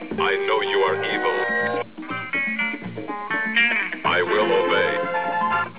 lc-robot.wav